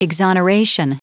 Prononciation du mot exoneration en anglais (fichier audio)
Prononciation du mot : exoneration